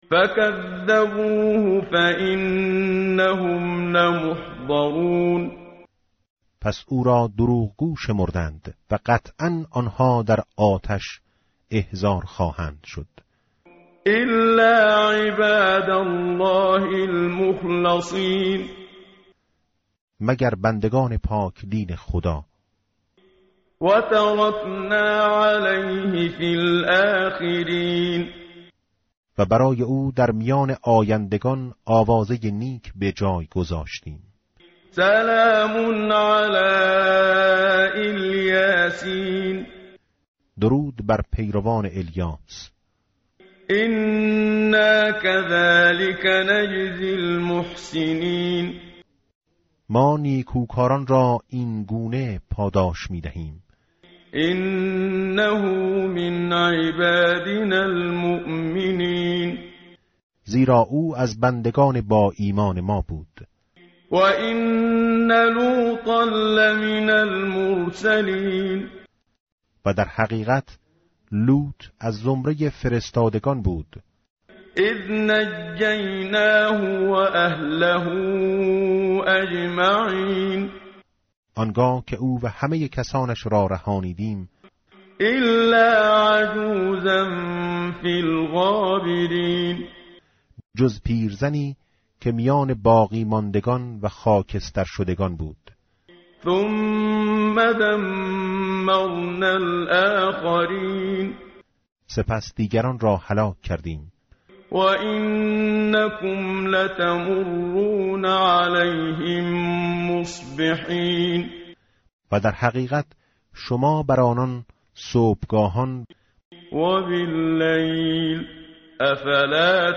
tartil_menshavi va tarjome_Page_451.mp3